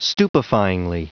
Prononciation du mot stupefyingly en anglais (fichier audio)
Prononciation du mot : stupefyingly